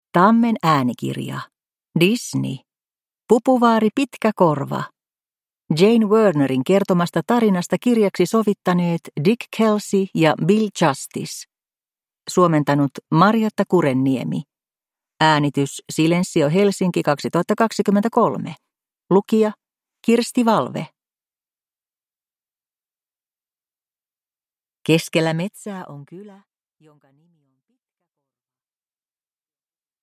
Disney. Pupuvaari Pitkäkorva – Ljudbok